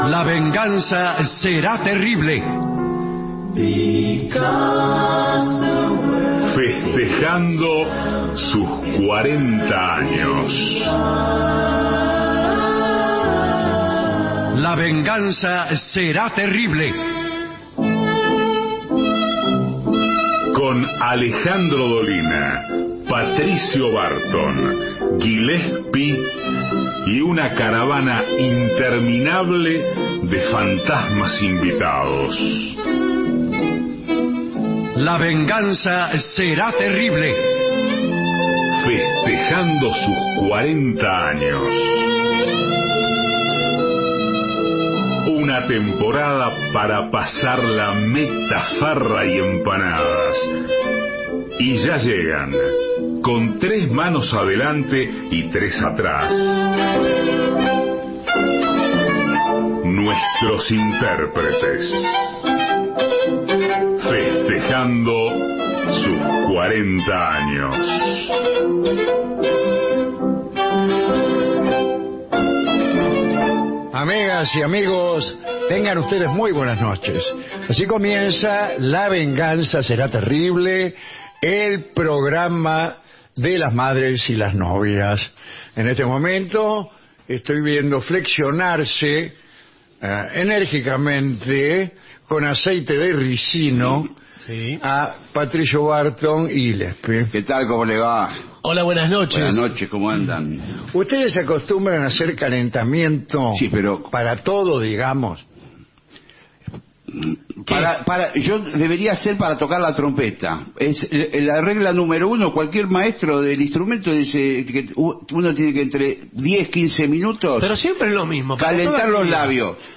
todo el año festejando los 40 años Estudios AM 750 Alejandro Dolina